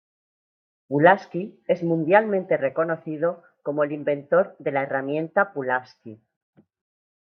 he‧rra‧mien‧ta
/eraˈmjenta/